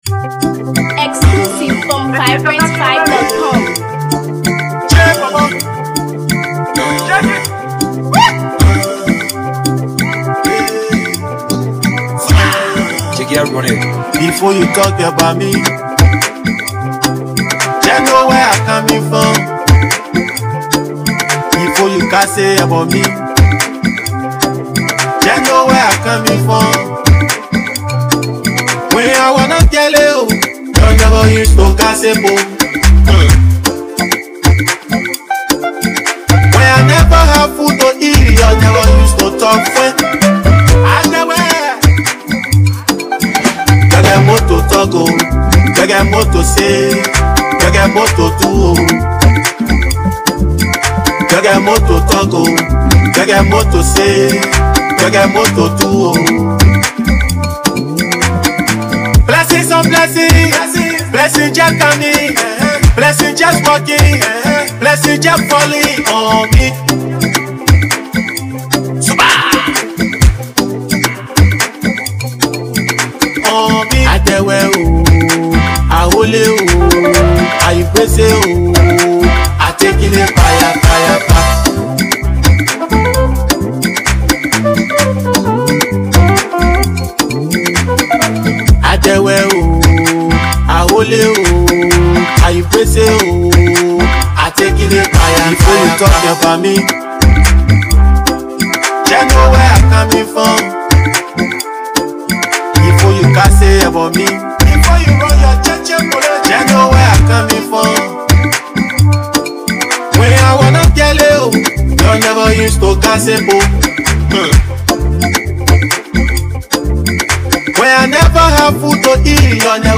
Liberian artist
Known for his energetic delivery and relatable lyrics